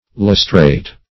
lustrate - definition of lustrate - synonyms, pronunciation, spelling from Free Dictionary